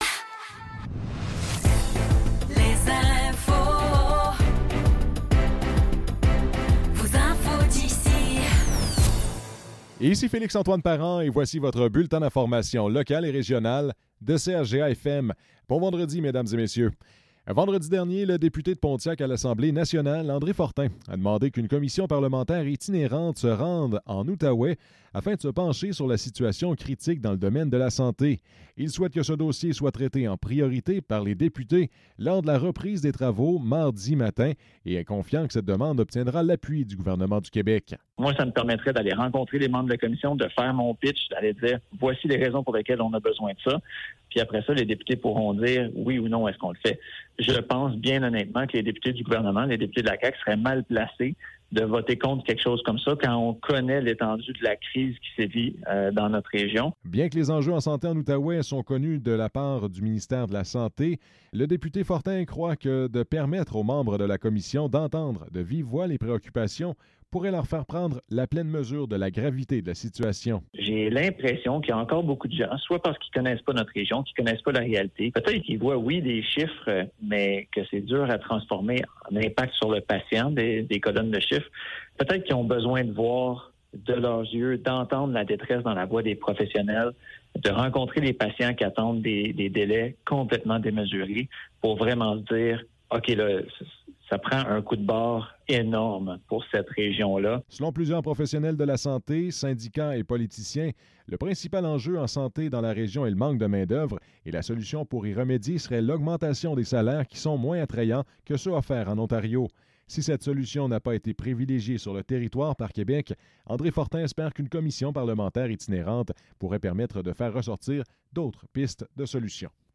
Nouvelles locales - 17 mai 2024 - 12 h